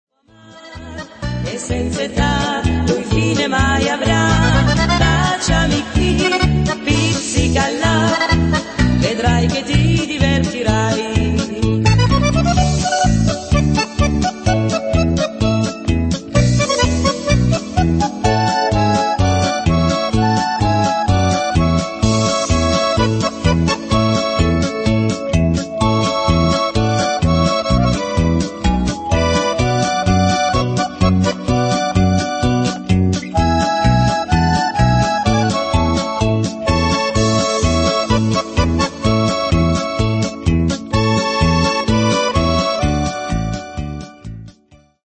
valzer musette